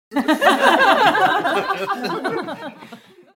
People Laughing Having A Good Time Sound Effect
Description: People laughing having a good time sound effect. A small group of six to seven women and men laughs sweetly with natural joy. This realistic laughter sound effect is perfect for films, videos, games, and sound design projects. Human sounds.
People-laughing-having-a-good-time-sound-effect.mp3